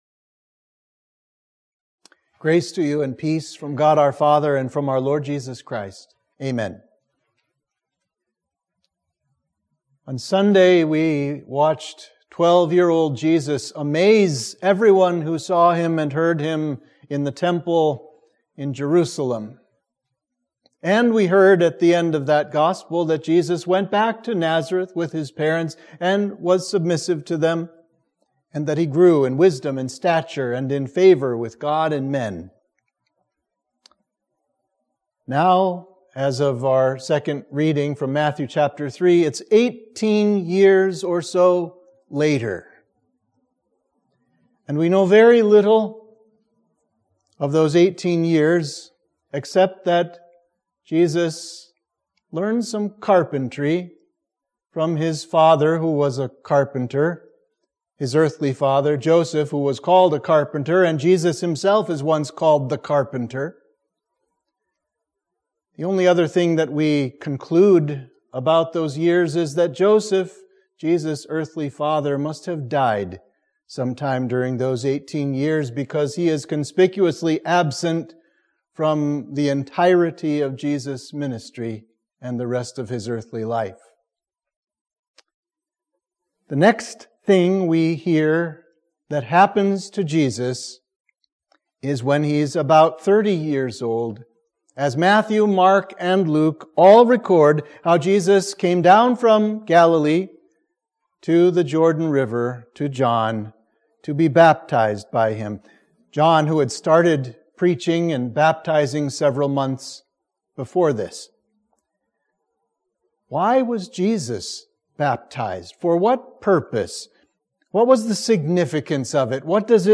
Sermon for the Baptism of Our Lord